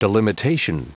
Prononciation du mot delimitation en anglais (fichier audio)